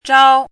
怎么读
zhāo
zhao1.mp3